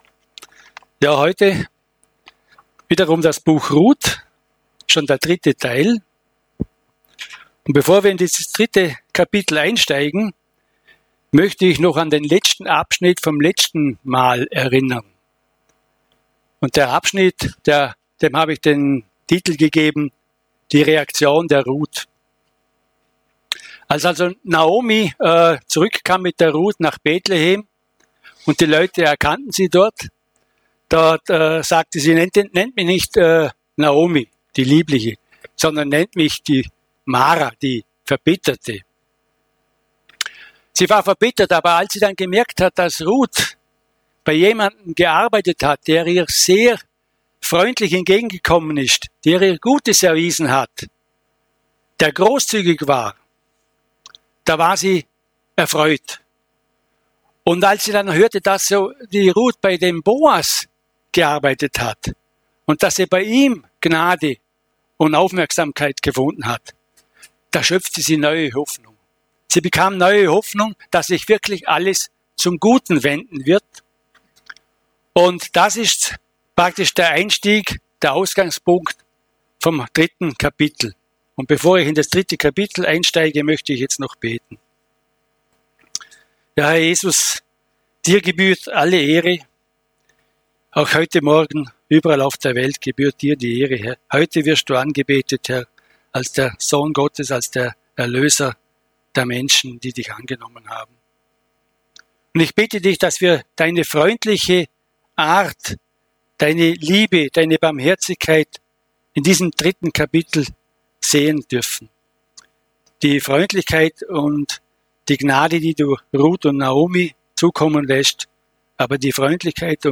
mit einer Predigt